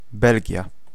Ääntäminen
Synonyymit Kingdom of Belgium Ääntäminen UK : IPA : [ˈbɛl.dʒəm] US : IPA : [ˈbɛl.dʒəm] Lyhenteet ja supistumat (laki) Belg.